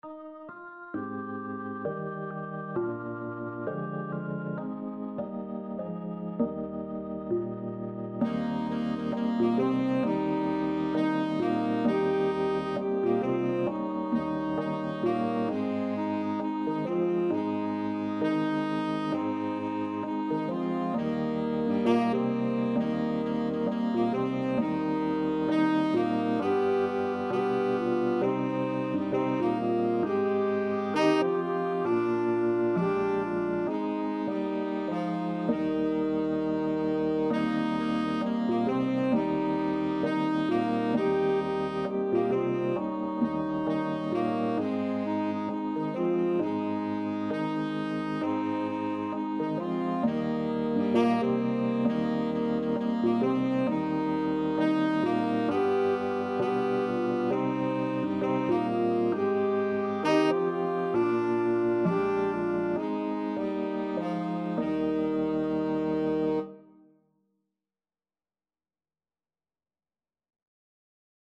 Alto SaxophoneTenor Saxophone
Pop (View more Pop Alto-Tenor-Sax Duet Music)